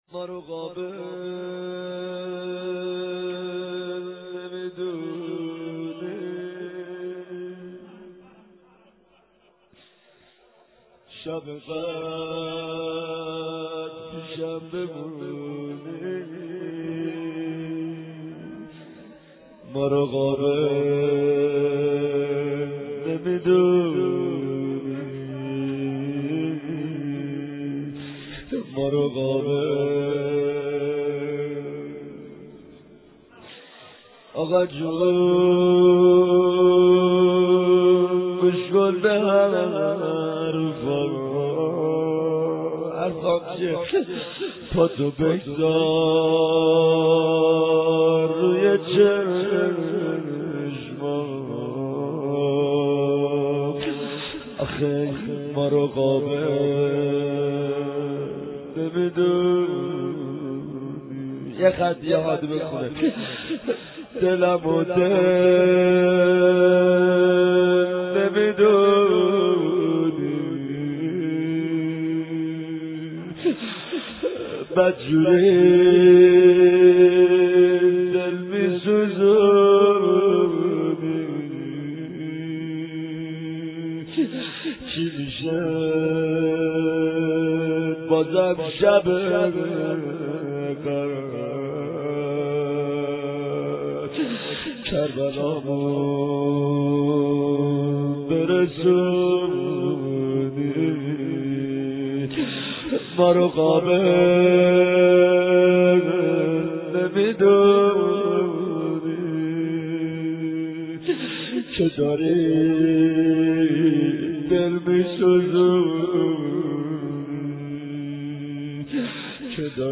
ما رو قابل نمى دونى شب قدر پيشم بمونى (نوحه)